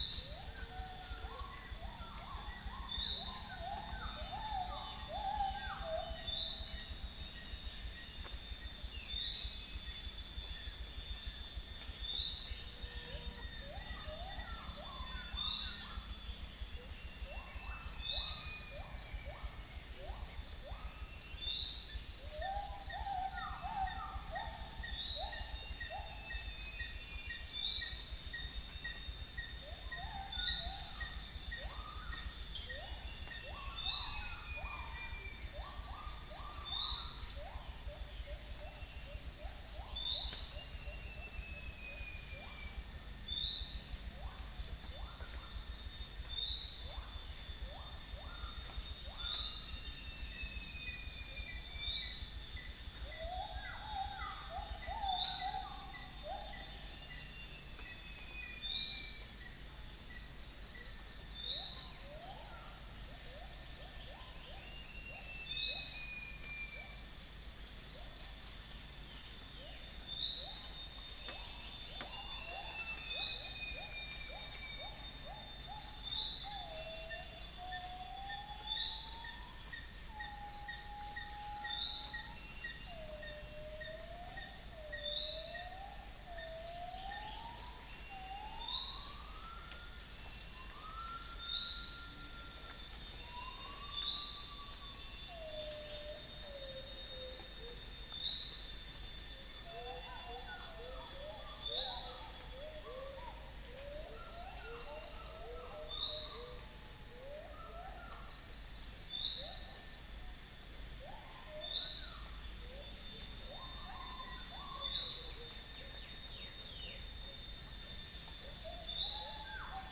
Upland plots dry season 2013
Geopelia striata
Trichastoma malaccense
Chloropsis moluccensis
2 - insect
Pellorneum nigrocapitatum